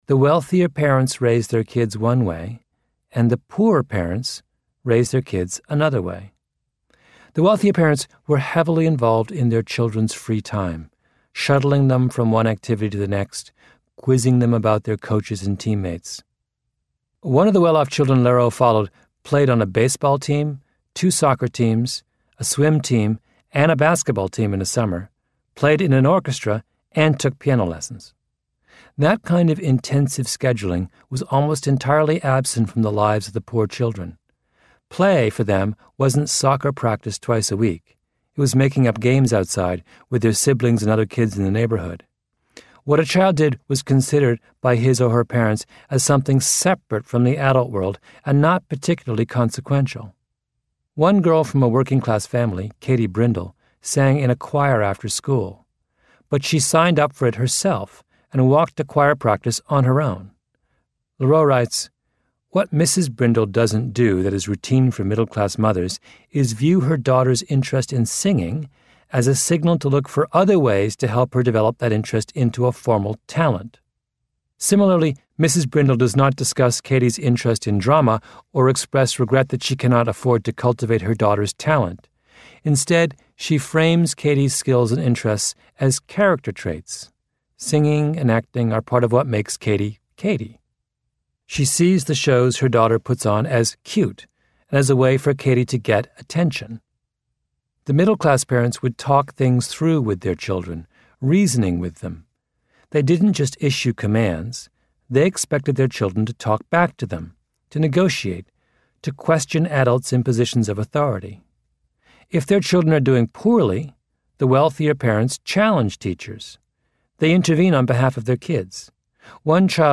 在线英语听力室异类之不一样的成功启示录 第67期:贫富家庭的教育区别的听力文件下载, 《异类:不一样的成功启示录Outliers:The Story of Success》是外文名著，是双语有声读物下面的子栏目，栏目包含中英字幕以及地道的英语音频朗读文件MP3，通过学习本栏目，英语爱好者可以懂得不一样的成功启示，并在潜移默化中挖掘自身的潜力。